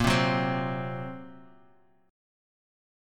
Bbsus2#5 chord